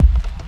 • Dark Techno Kick UK Style.wav
Dark_Techno_Kick_UK_Style_0jP.wav